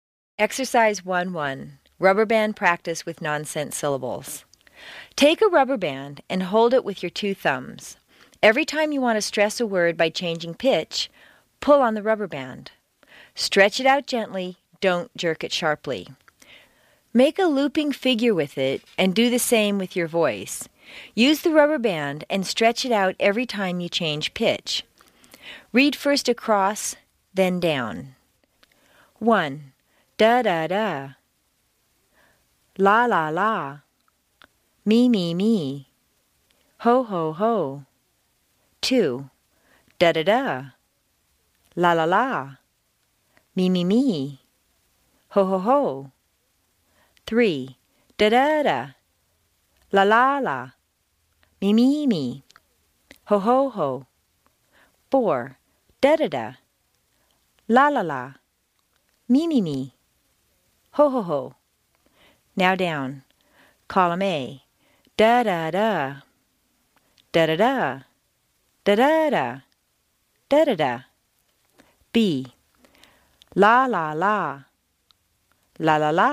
美式英语正音训练第2期:练习1 听力文件下载—在线英语听力室
在线英语听力室美式英语正音训练第2期:练习1的听力文件下载,详细解析美式语音语调，讲解美式发音的阶梯性语调训练方法，全方位了解美式发音的技巧与方法，练就一口纯正的美式发音！